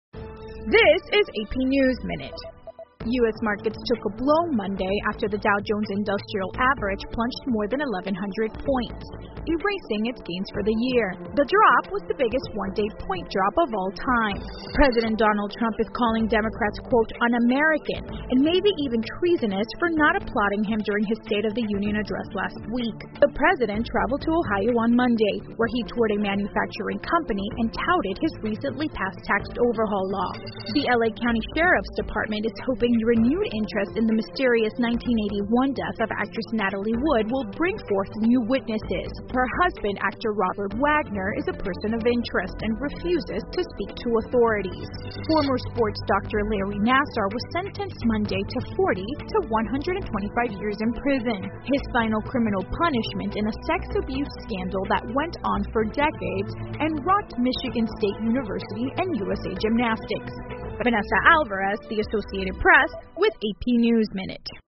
美联社新闻一分钟 AP 听力文件下载—在线英语听力室